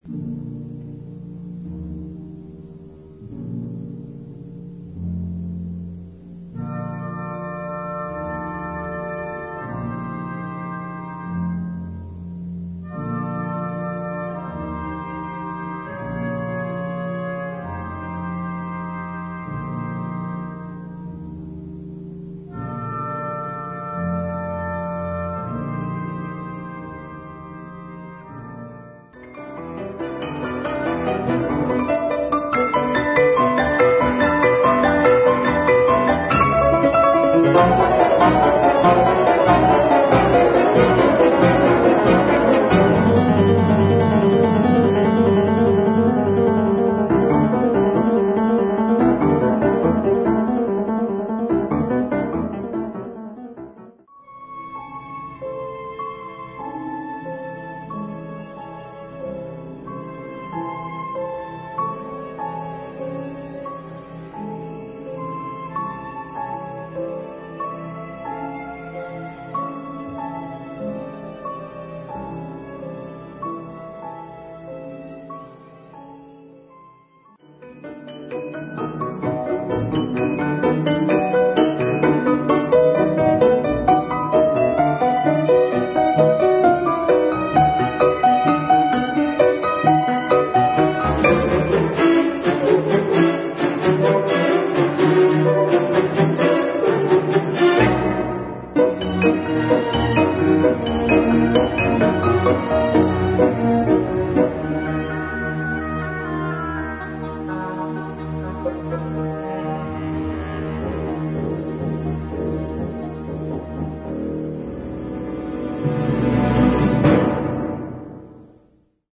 na 2 fortepiany i orkiestrę